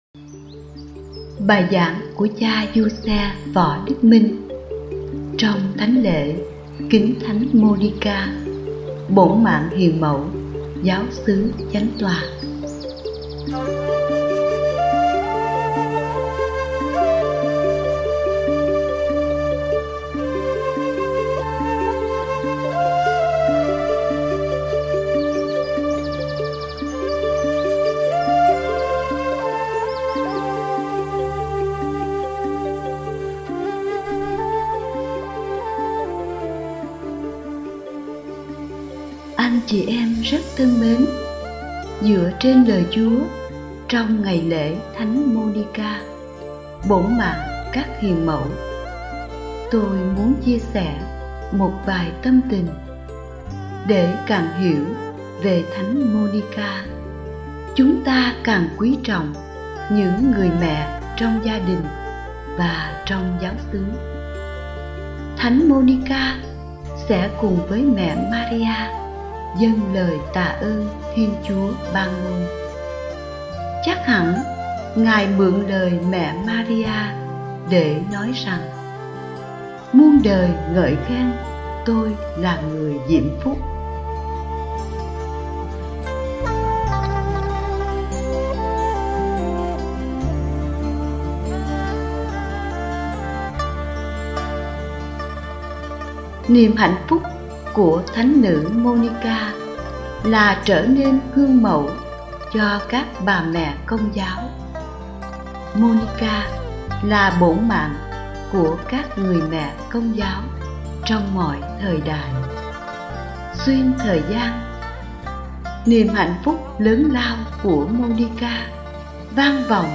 Bài giảng Lễ kính Thánh Nữ Monica